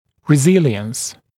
[rɪ’zɪlɪəns][ри’зилиэнс]эластичность (о дуге), гибкость, упругость